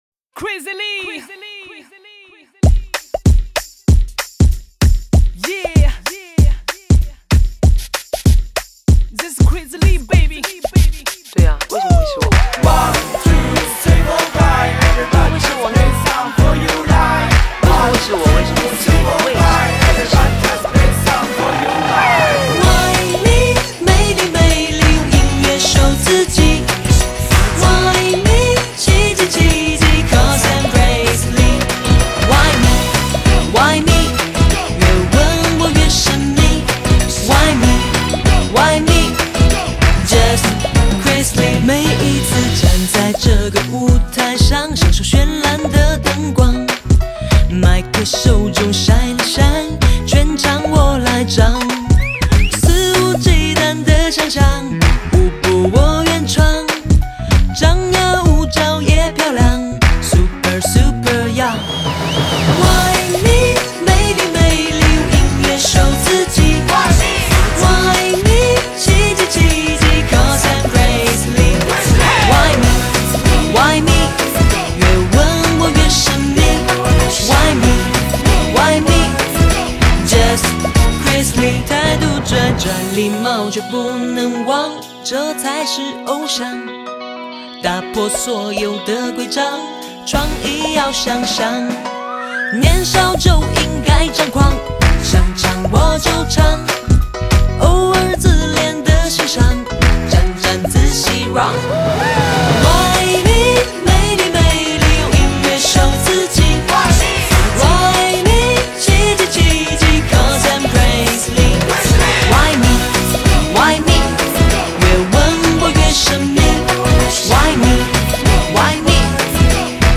舞曲
整首歌节奏动感明 快，旋律BALA上口，同时也参入了不少听众的互动元素，是HIGH 爆现场的首选歌曲。